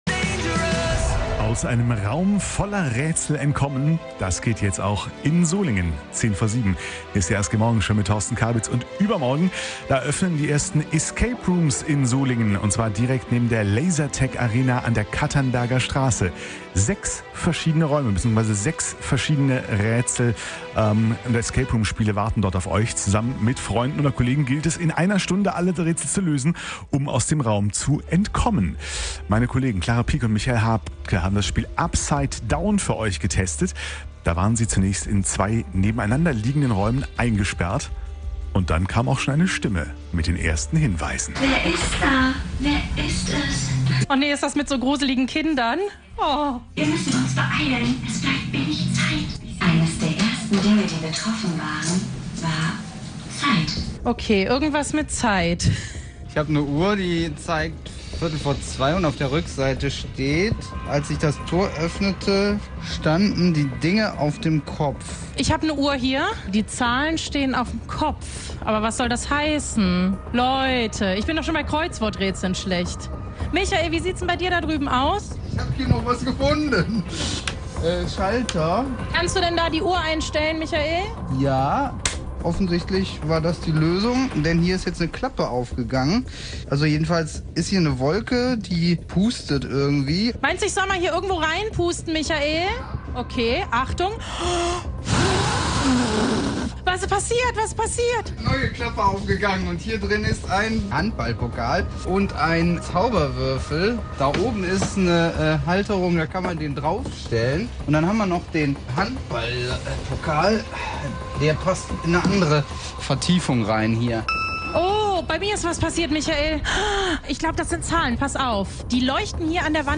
RSG-MorgenshowEscape Room Arena Solingen - Reportage